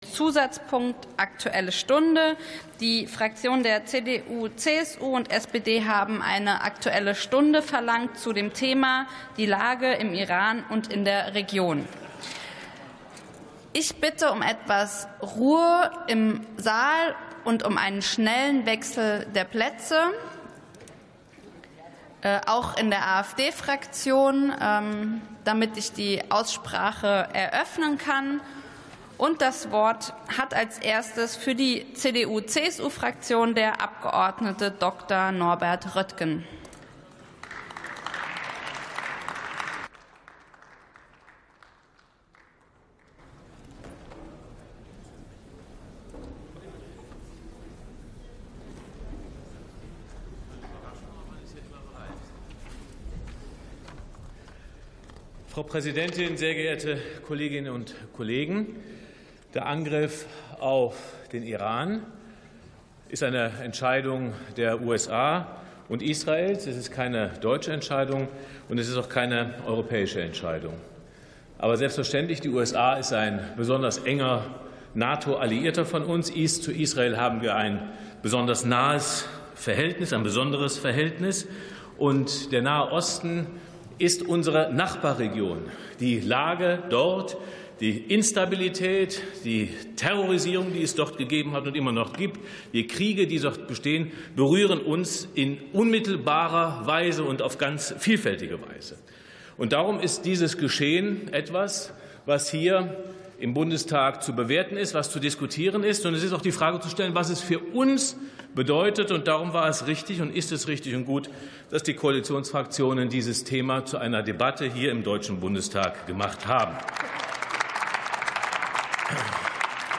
Sitzung vom 04.03.2026. TOP ZP 1: Aktuelle Stunde: Die Lage im Iran und in der Region ~ Plenarsitzungen - Audio Podcasts Podcast